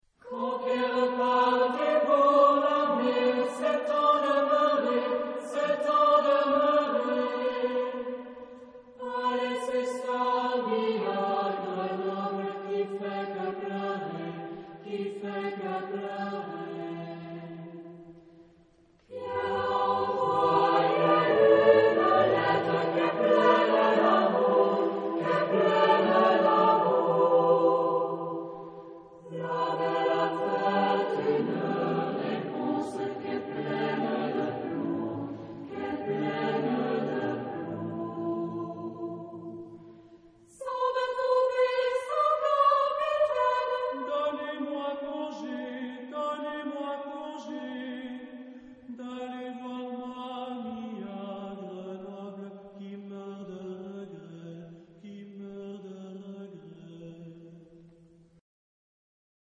Genre-Style-Form: Partsong ; Folk music ; Secular
Mood of the piece: poignant
Type of Choir: SATB  (4 mixed voices )
Soloist(s): Tenor (1)  (2 soloist(s))
Tonality: dorian